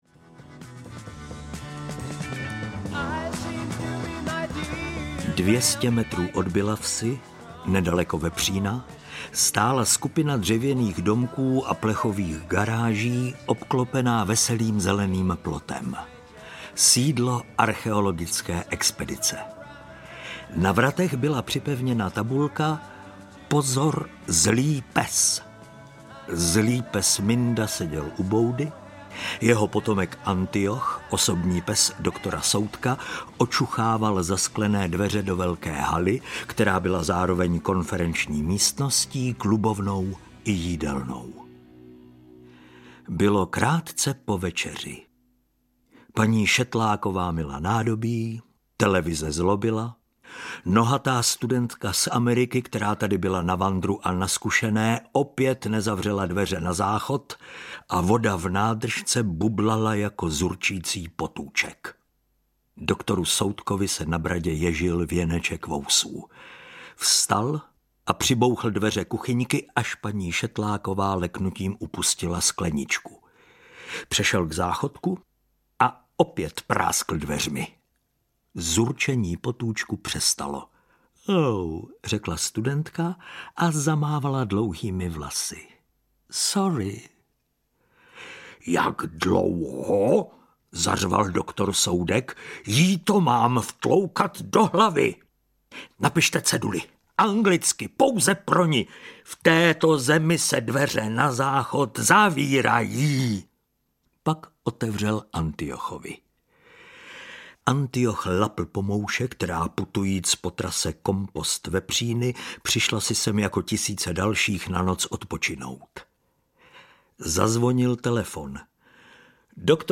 Vražda pro Zlatého muže audiokniha
Ukázka z knihy